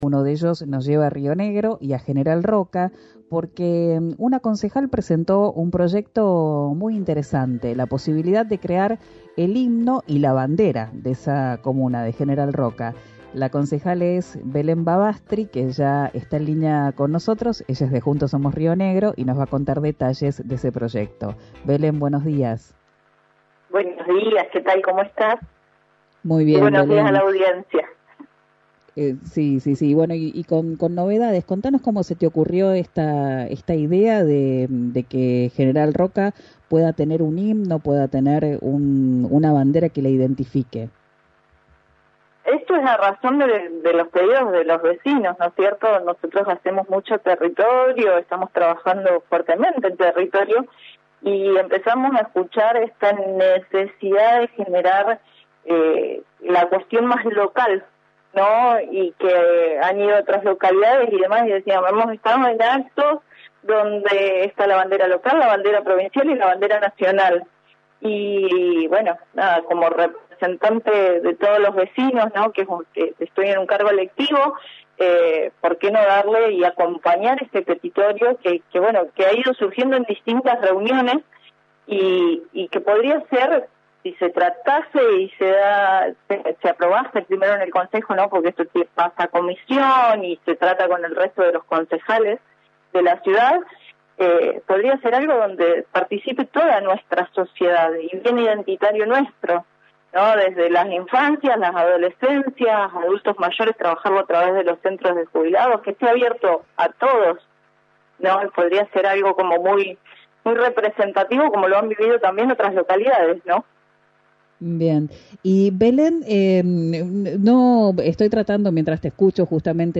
Escuchá a Belén Bavastri, concejal de JSRN, en RÍO NEGRO RADIO: